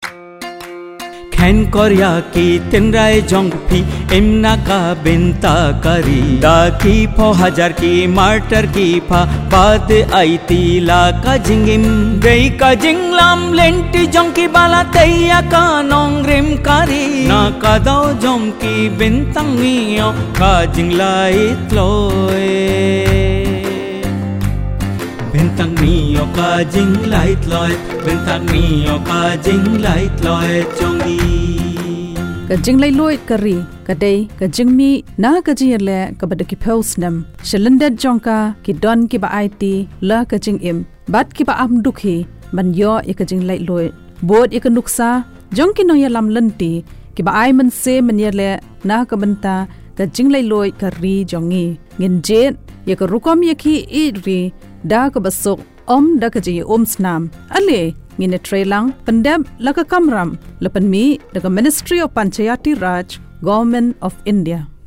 Radio Jingle